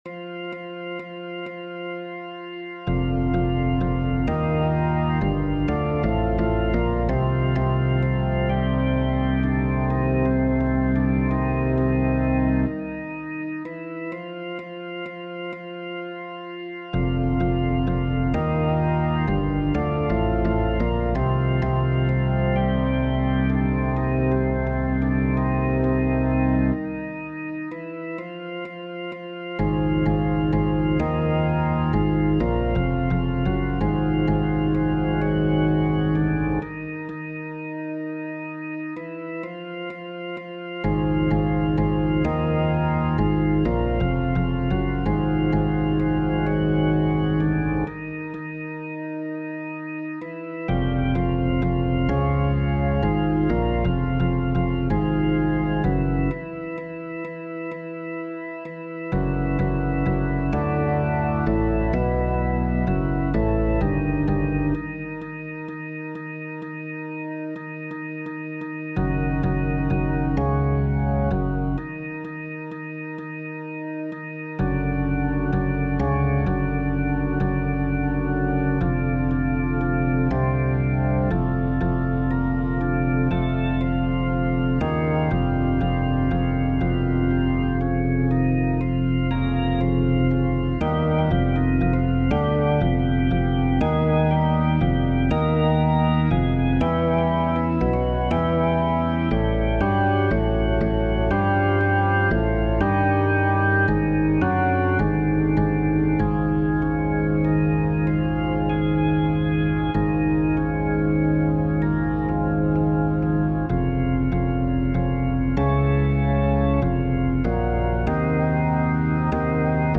FF:HV_15b Collegium male choir
Kladeni-Bas2.mp3